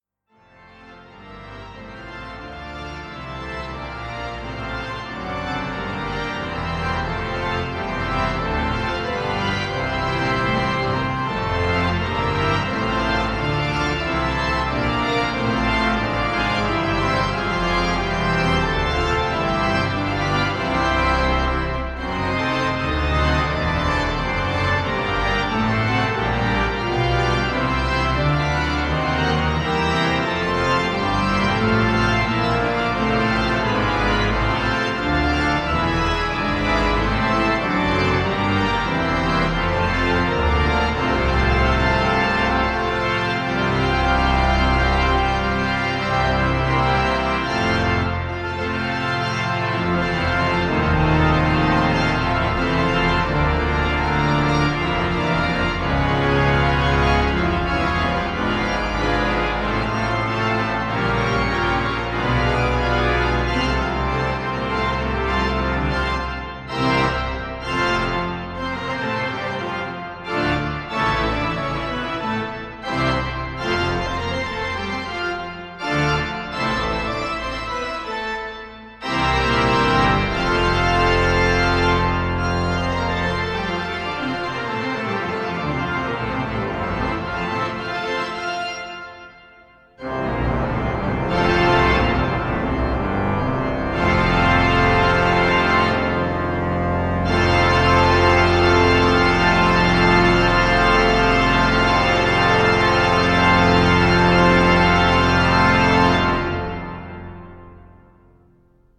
Sound Extracts From the Rieger Organ
The Rieger Organ of Christchurch Town Hall, New Zealand
Recorded in Christchurch Town Hall 29-31 January 2010.